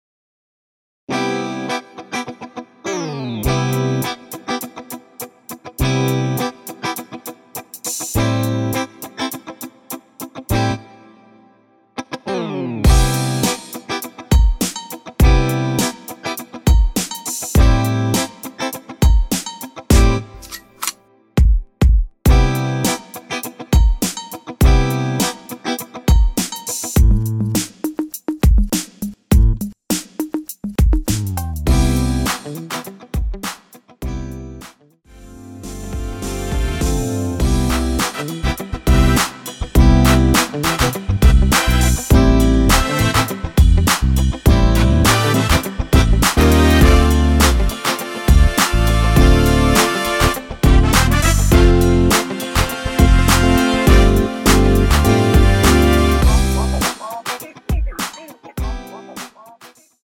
전주 없이 시작 하는 곡이라서 1마디 전주 만들어 놓았습니다.(미리듣기 확인)
3초쯤 노래 시작 됩니다.
원키에서(-1)내린 MR입니다.
앞부분30초, 뒷부분30초씩 편집해서 올려 드리고 있습니다.
중간에 음이 끈어지고 다시 나오는 이유는